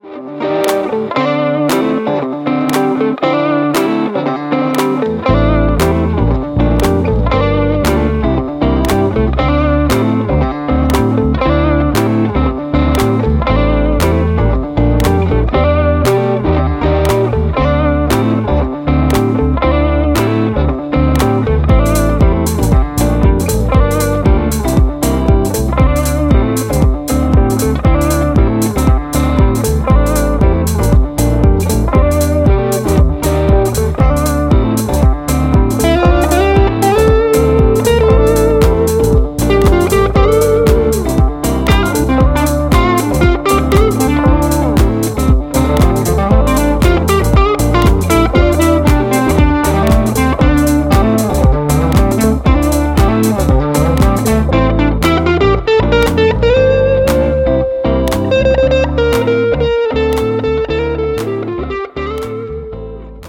(Live Edit)